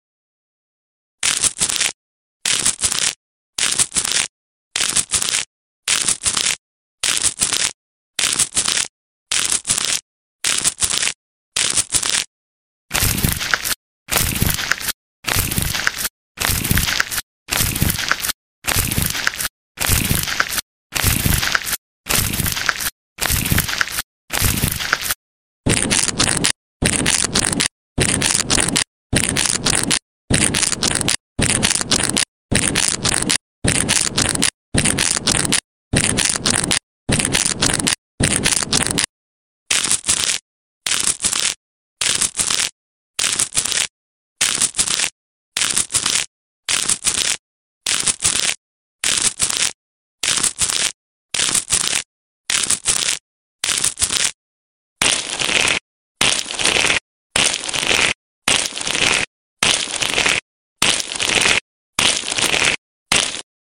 ASMR foot spa & massage sound effects free download